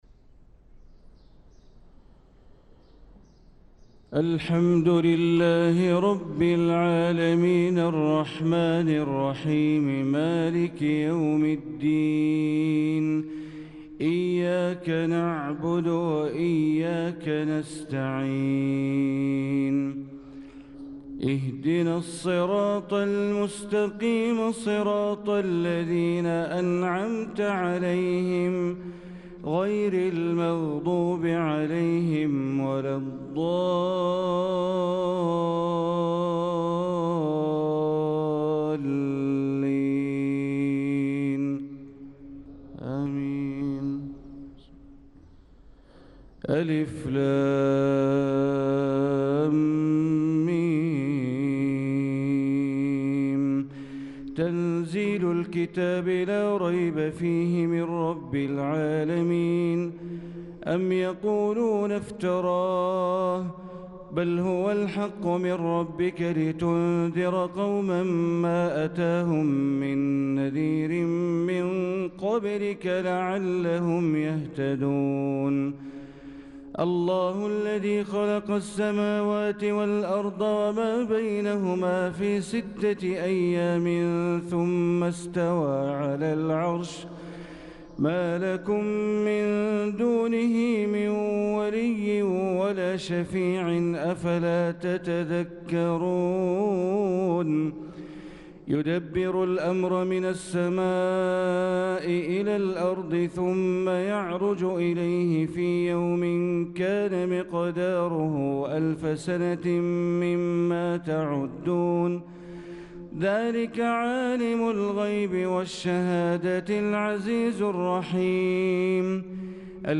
صلاة الفجر للقارئ بندر بليلة 23 ذو القعدة 1445 هـ
تِلَاوَات الْحَرَمَيْن .